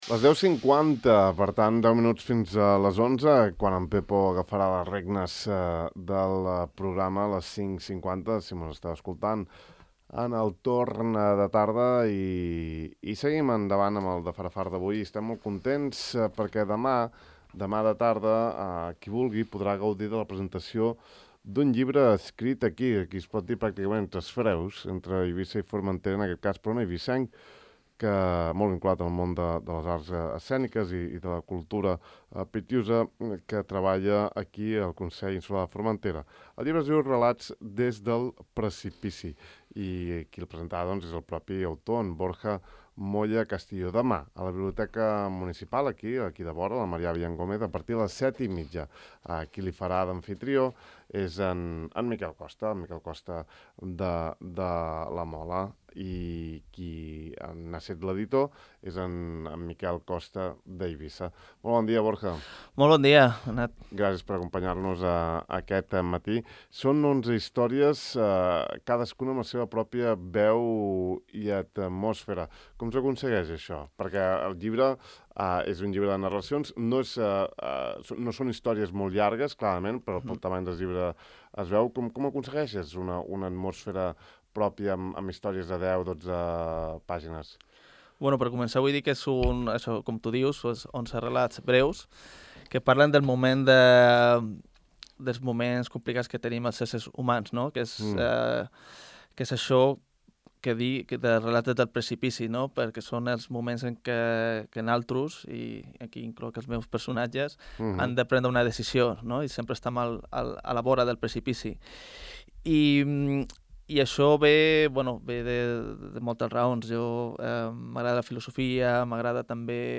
Aquest matí hi hem parlat al De far a far: